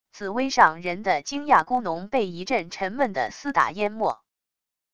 紫微上人的惊讶咕哝被一阵沉闷的撕打淹没wav音频